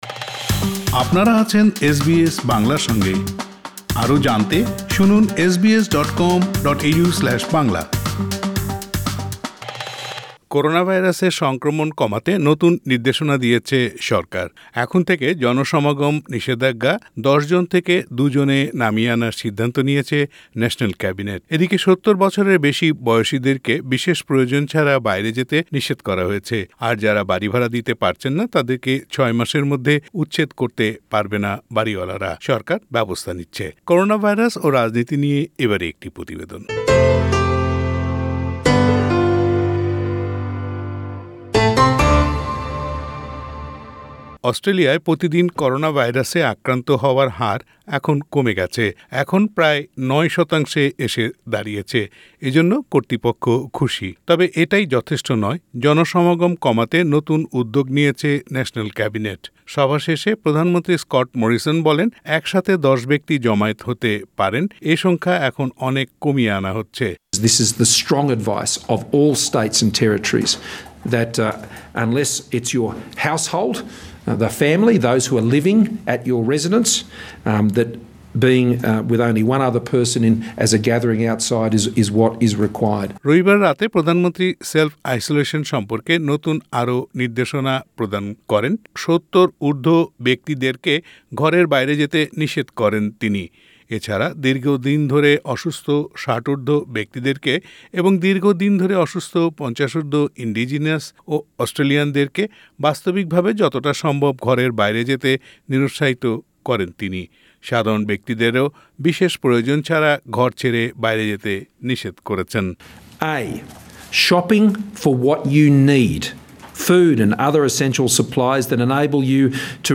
করোনাভাইরাস ও রাজনীতি নিয়ে প্রতিবেদনটি শুনতে উপরের অডিও প্লেয়ারটিতে ক্লিক করুন।